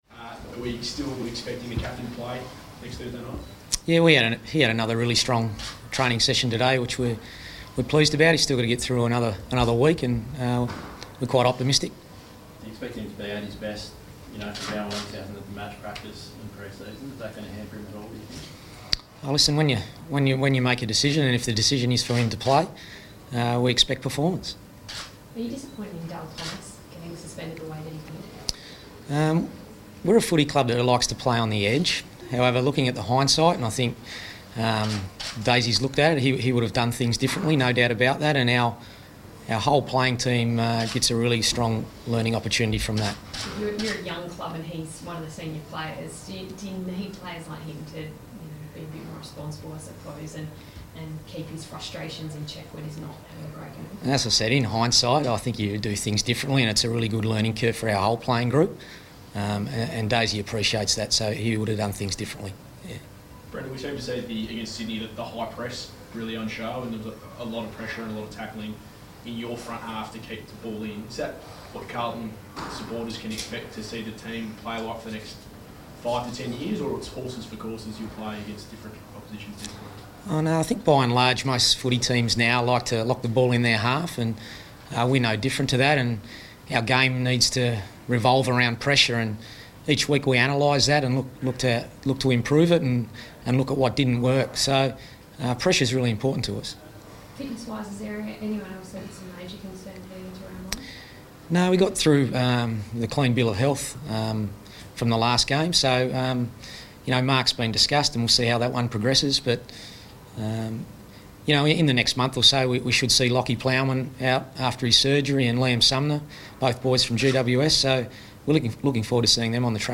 Brendon Bolton press conference - March 16
Coach Brendon Bolton fronts the media at Ikon Park.